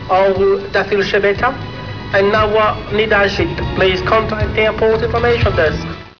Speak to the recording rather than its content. "We'd go and sit on the balcony at Terminal 3 at Heathrow, directly under one of the speakers as the roof is low. We put the tape machine in our bag with the microphone poking out of the top.